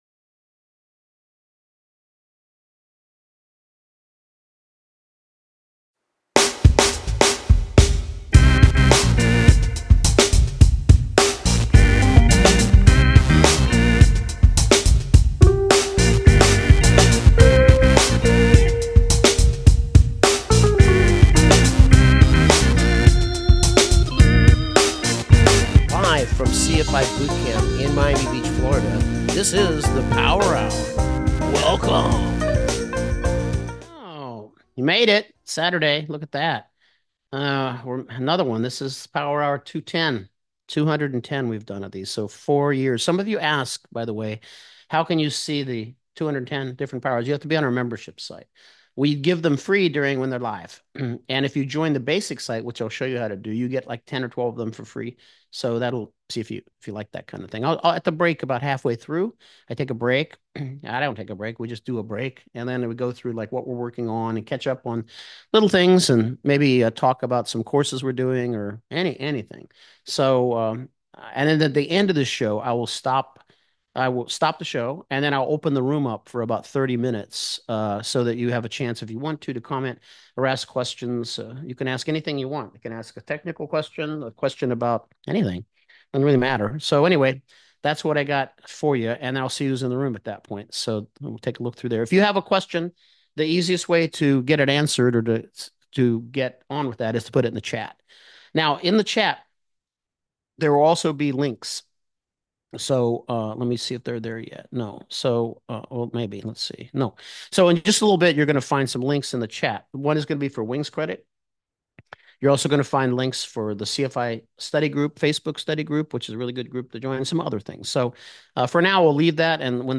This Free Training Resource provides a ground lesson on the Steps to Take to Become a CFI.